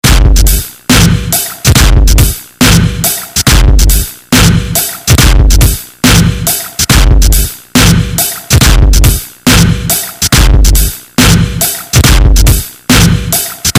基本爵士乐节拍
标签： 75 bpm Drum And Bass Loops Percussion Loops 551.36 KB wav Key : C
声道立体声